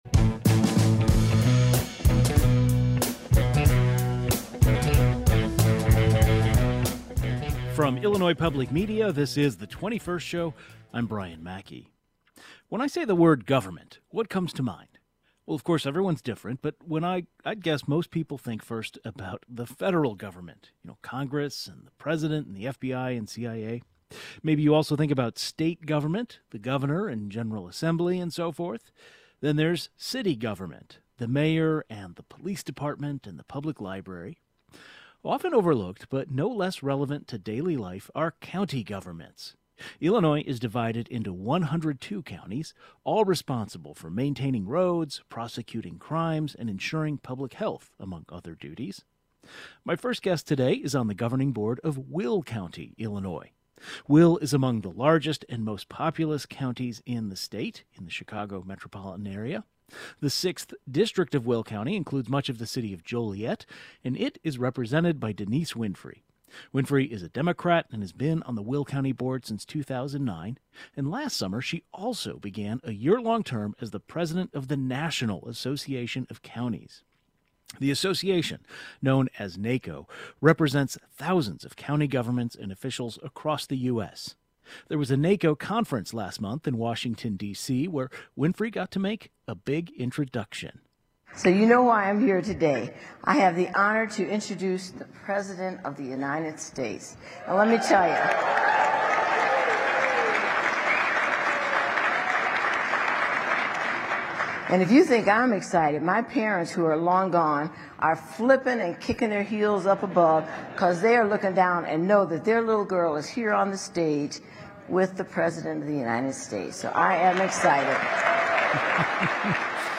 The 21st was joined by the president of the National Association of Counties, who also serves on the Will County Board.